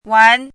chinese-voice - 汉字语音库
wan2.mp3